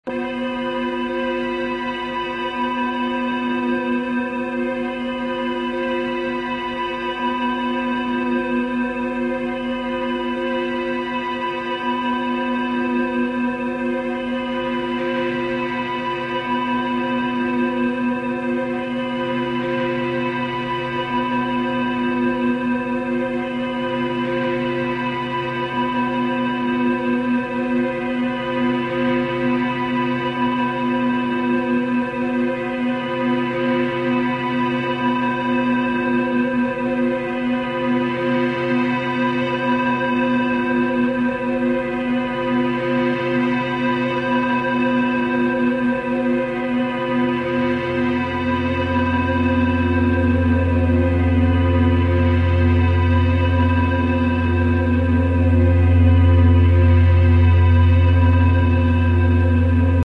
drone music
Electronix Ambient